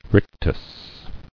[ric·tus]